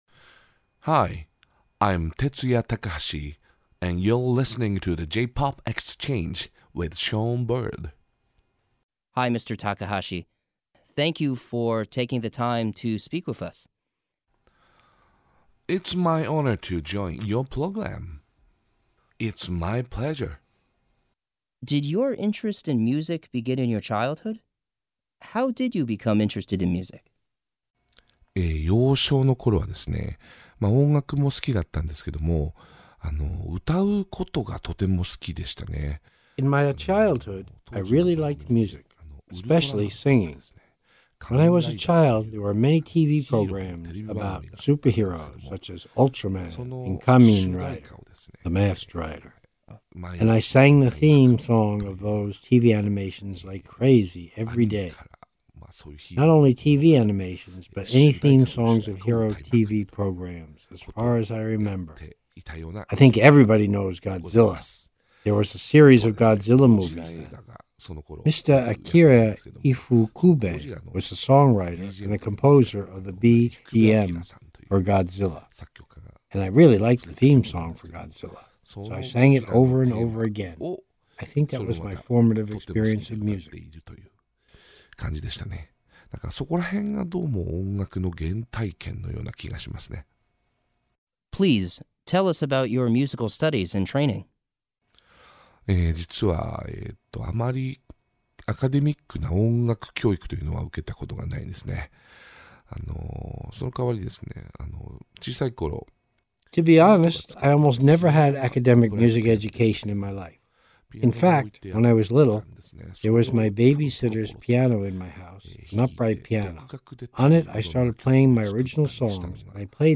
View Transcript of Radio Interview